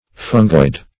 Definition of fungoid.
Fungoid \Fun"goid\, a.